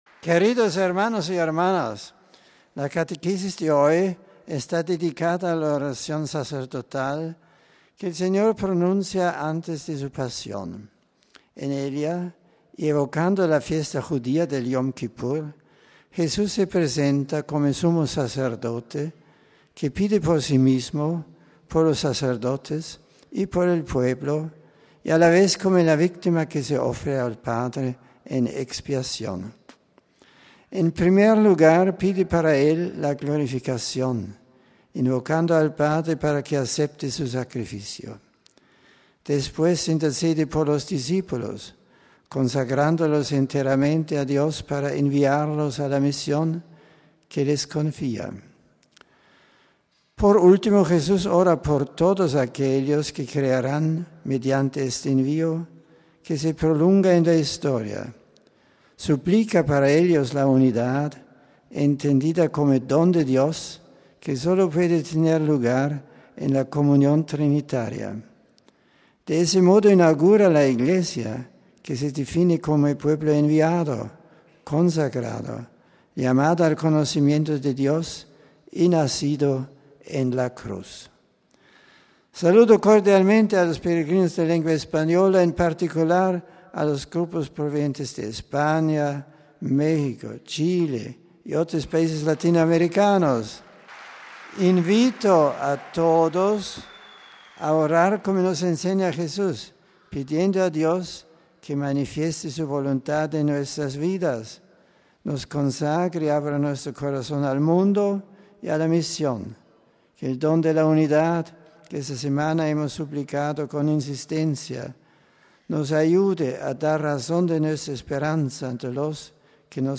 TEXTO CATEQUESIS Y SALUDOS DE BENEDICTO XVI AUDIENCIA GENERAL 250112